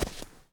update snow step sounds
snow_4.ogg